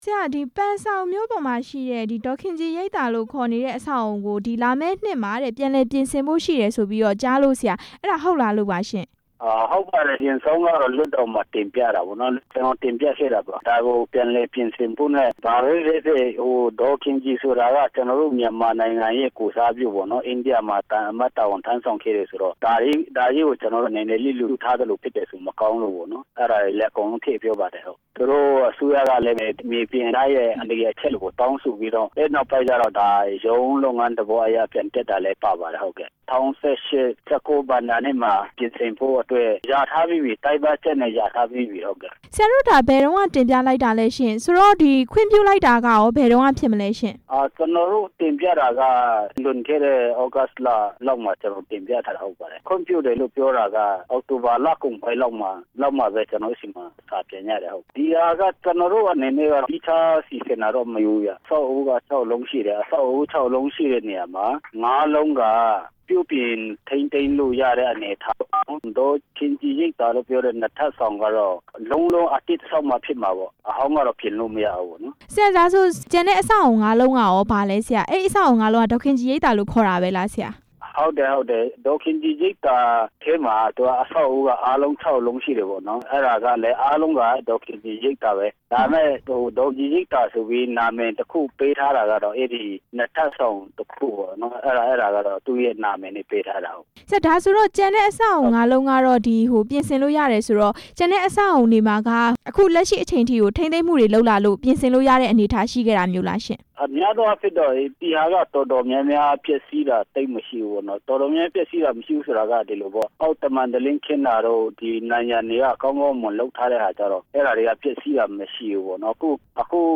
ပန်ဆောင်မြို့က ဒေါ်ခင်ကြည်ရိပ်သာ ပြန်လည်ပြုပြင်မယ့်အကြောင်း မေးမြန်းချက်